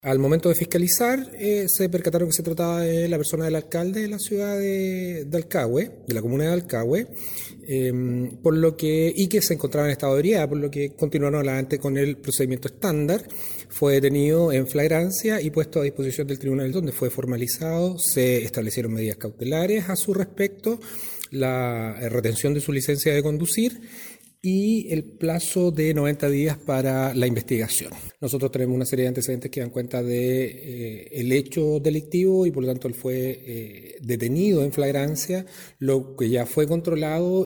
De esta manera lo señaló el fiscal del ministerio público de la capital provincia, Enrique Canales, quien detalló que según se reportó por Carabineros de Dalcahue, se le fiscalizó luego de protagonizar un accidente al impactar un poste del alumbrado público.